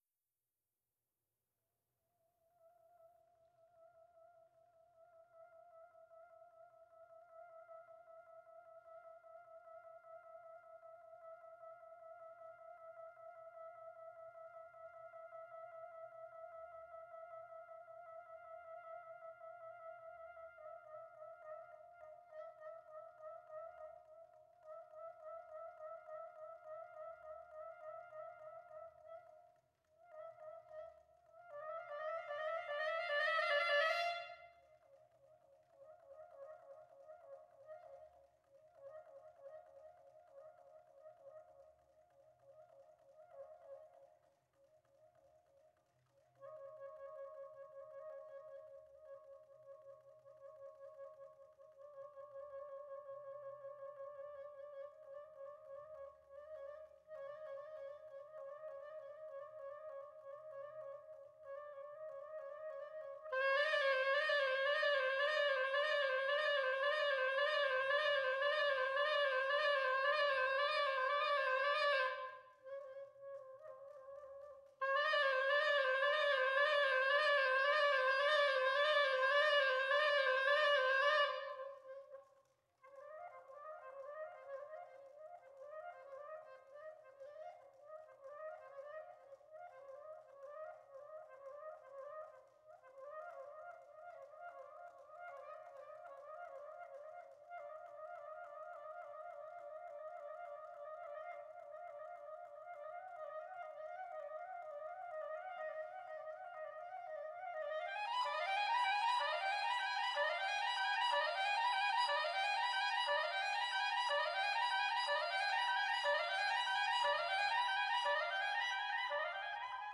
at the Wayne State University in 2017.